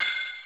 Index of /90_sSampleCDs/Zero-G Groove Construction (1993)/Drum kits/Euro techno/Hits & FX